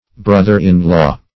Brother-in-law \Broth"er-in-law`\, n.; pl. Brothers-in-law.